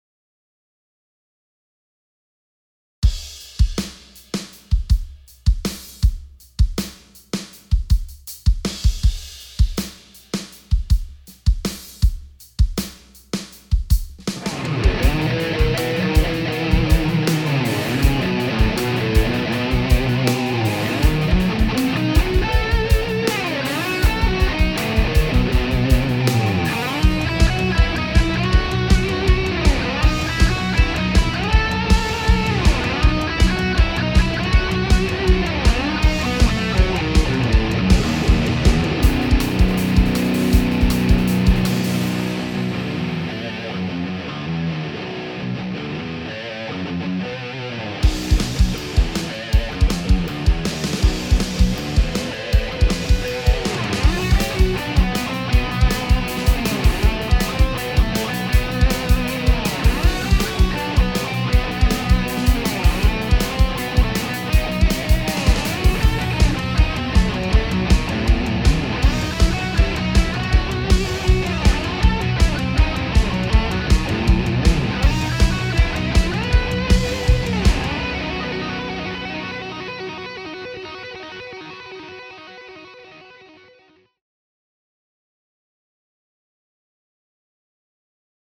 Sorry für den miesen Sound Als ich das aufgenommen habe (2020/2021) hatte ich quasi null Plan von Cubase und spielte erst ein paar Monate wieder.